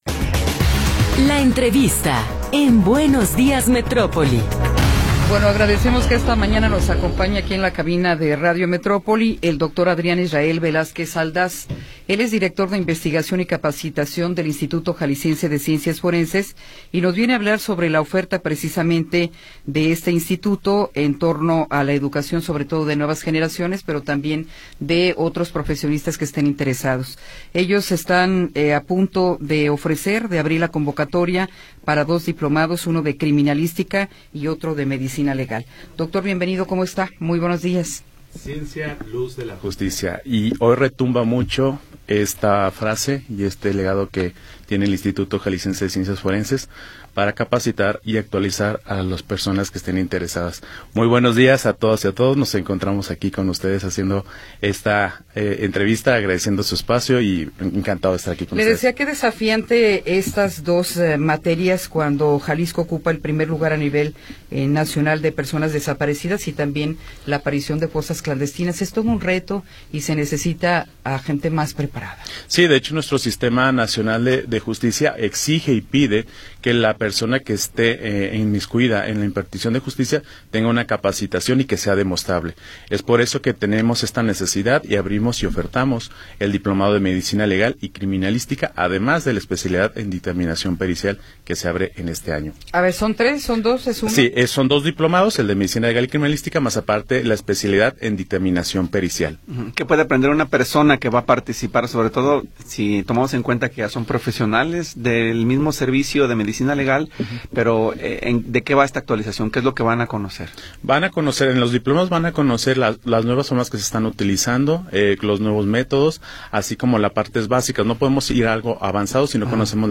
Notisistema
Entrevista